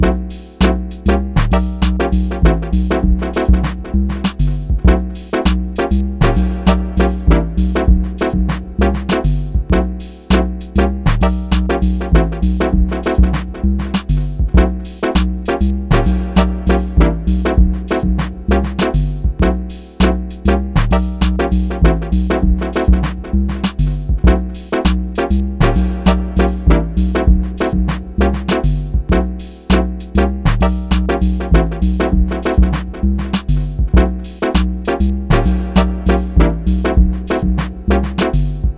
(I keep posting loops with the looptober tag so that they loop for friendcamp listeners)